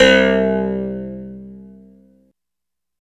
27 CLAV C2-R.wav